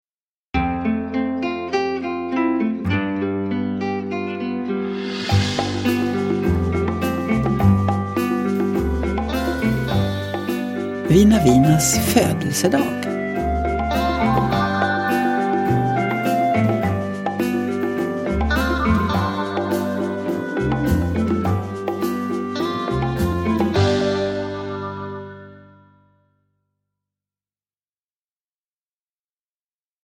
Vina Vinas födelsedag – Ljudbok – Laddas ner
Uppläsare: Jujja Wieslander